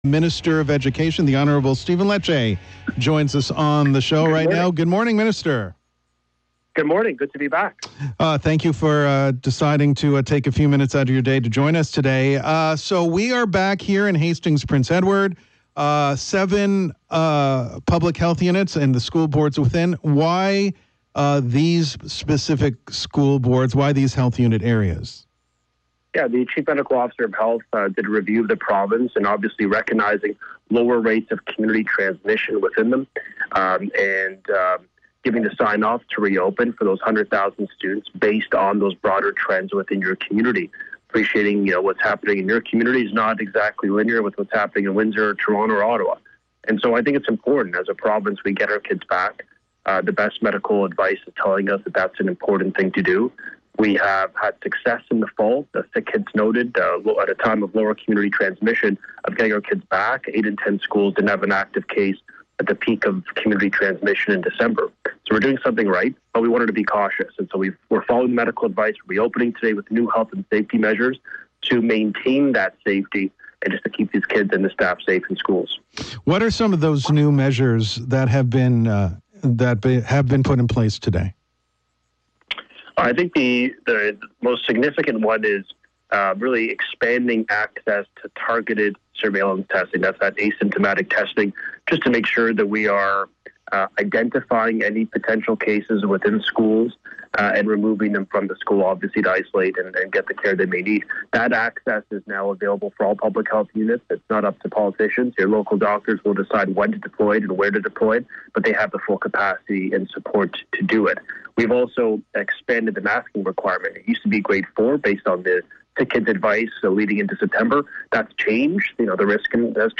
Minister of Education Stephen Lecce